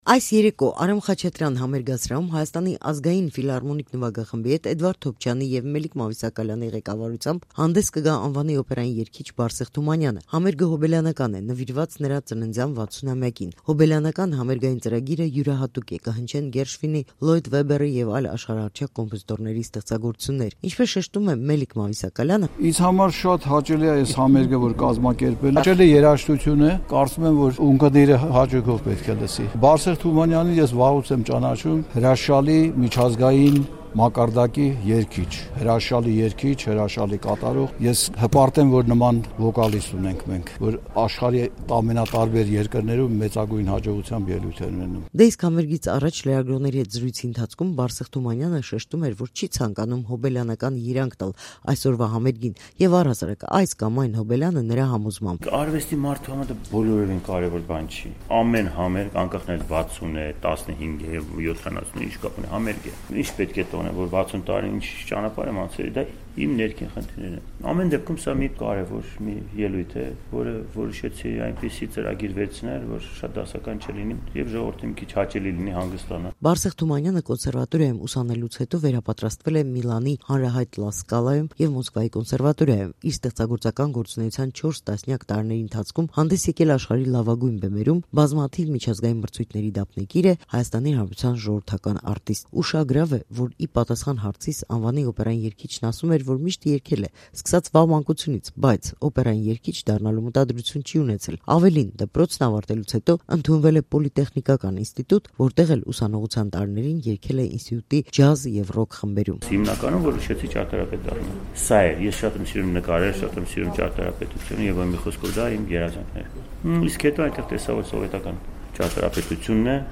Հոբերլյանական համերգ՝ նվիրված Բարսեղ Թումանյանի ծննդյան 60-ամյակին
Ռեպորտաժներ